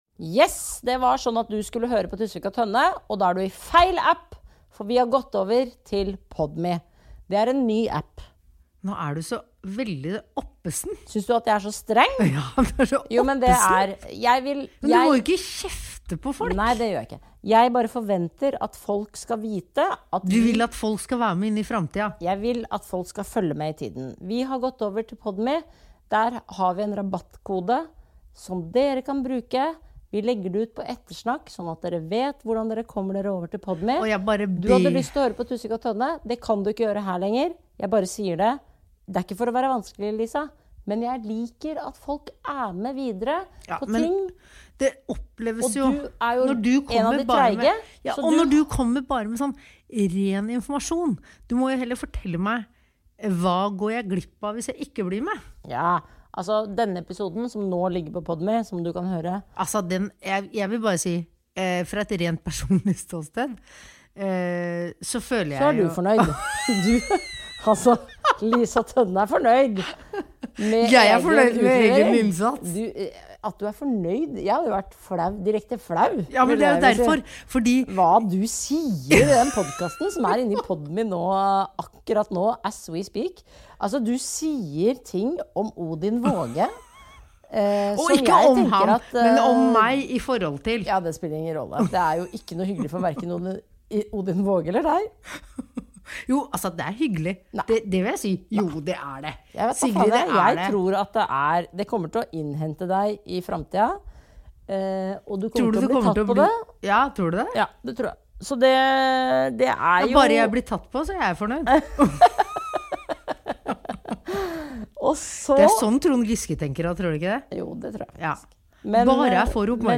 Sigrid og Lisa er to altfor frittalende komikere.